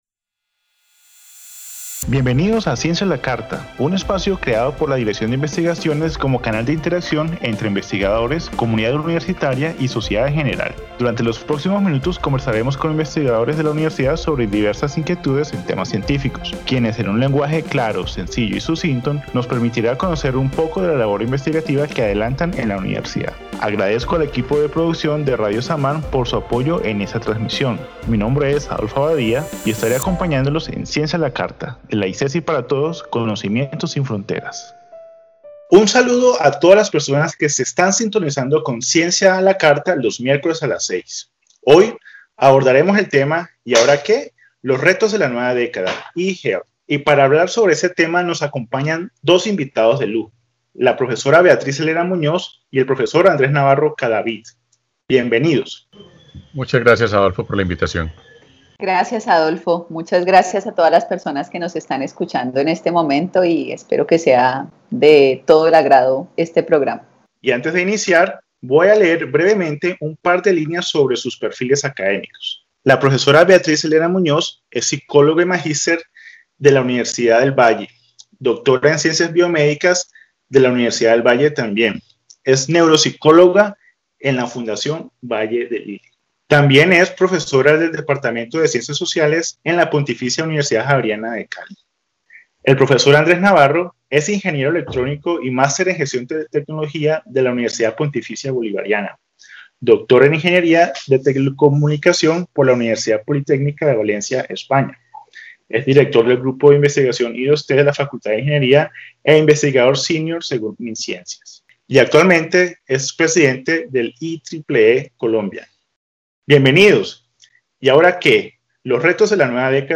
En el primer momento del programa, los investigadores invitados tendrán un espacio para reaccionar, libremente, al tema del día y, posteriormente, se traerán a la mesa preguntas formuladas previamente por el público para ser abordadas por medio del diálogo con los expertos invitados.